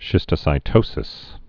(shĭstə-sī-tōsĭs)